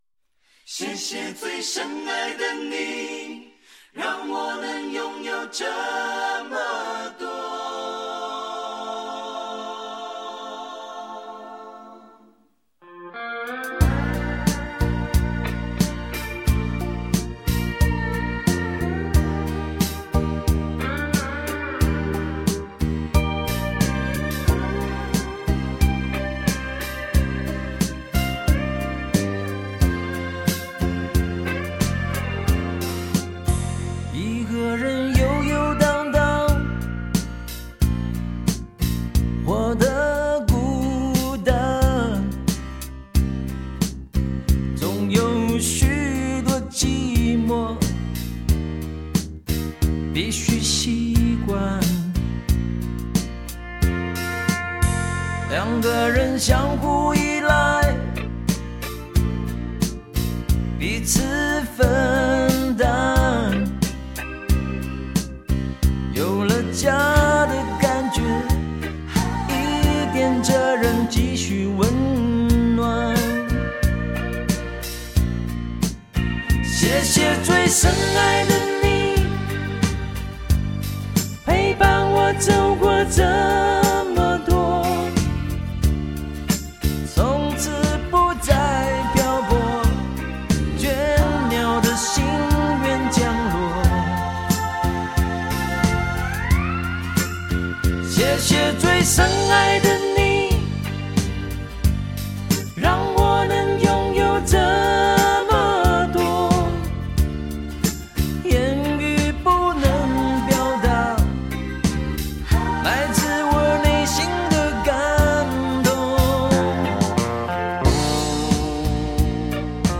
整张专辑的音乐做的婉转圆熟，歌者演绎能力不凡。